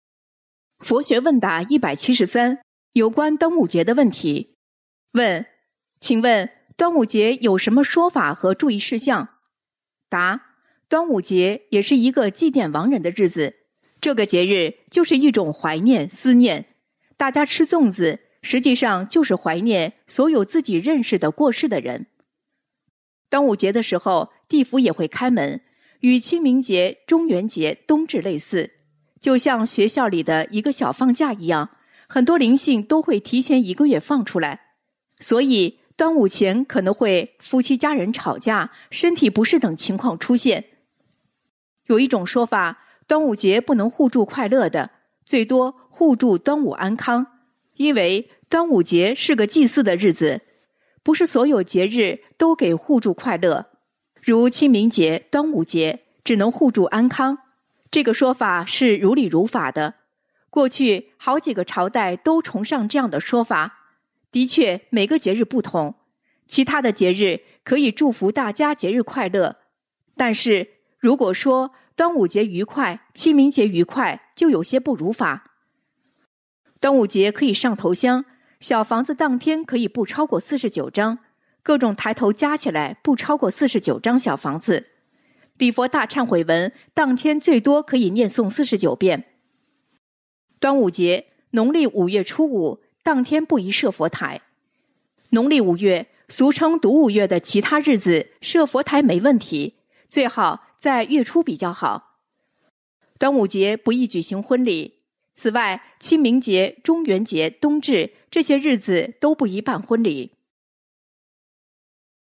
有声书【佛学问答】173 有关端午节的问题